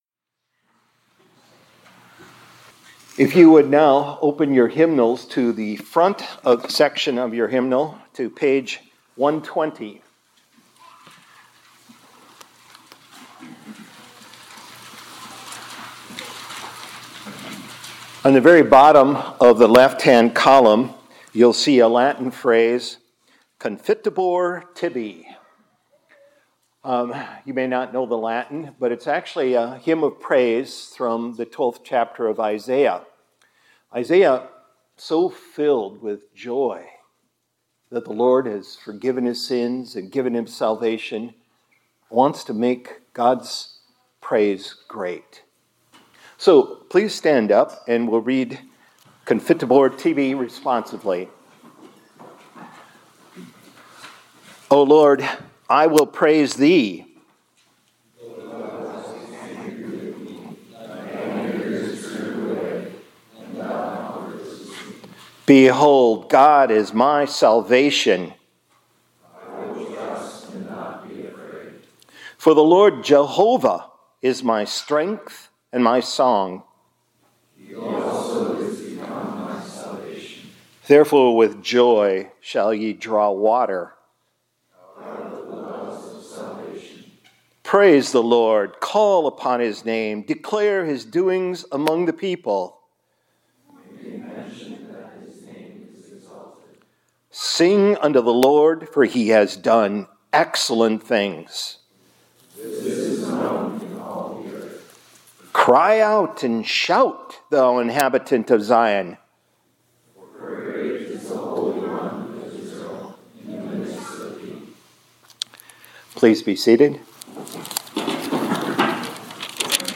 2025-02-13 ILC Chapel — Confitebor Tibi